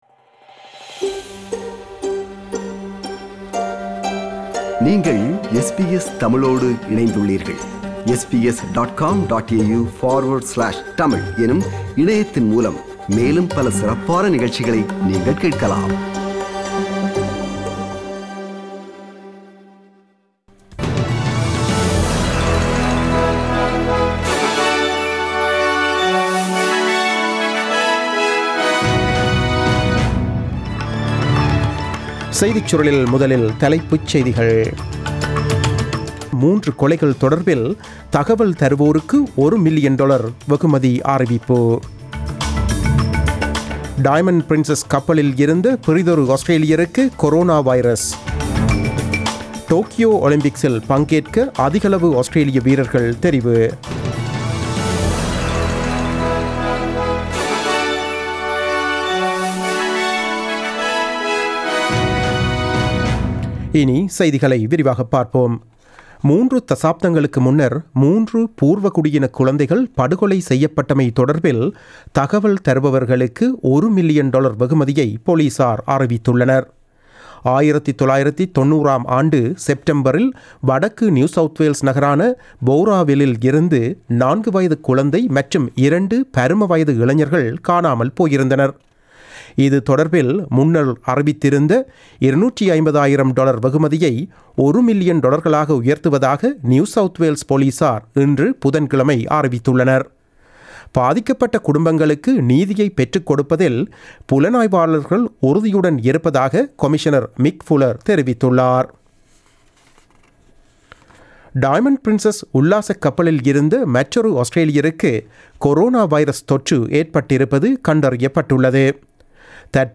நமது SBS தமிழ் ஒலிபரப்பில் இன்று புதன்கிழமை (26 February 2020) இரவு 8 மணிக்கு ஒலித்த ஆஸ்திரேலியா குறித்த செய்திகள்.